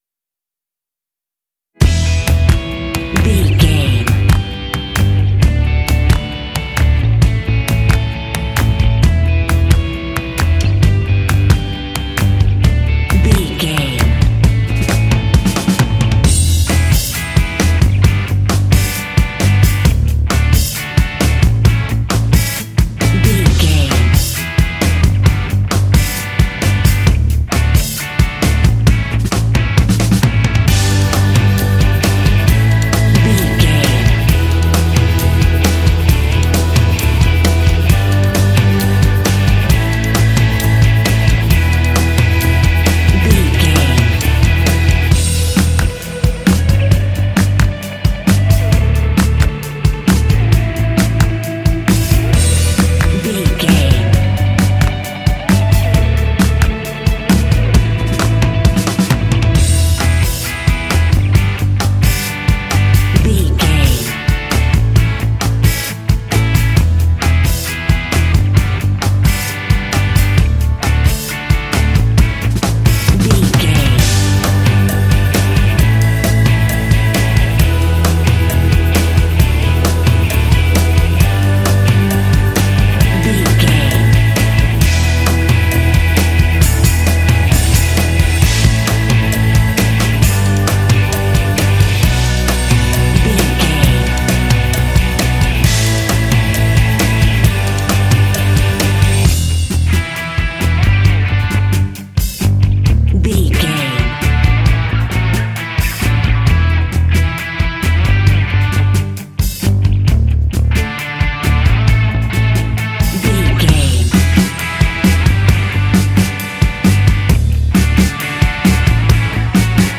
This rock/pop track is ideal for kids games and board games.
Ionian/Major
energetic
bright
lively
sweet
electric guitar
drums
bass guitar
electric organ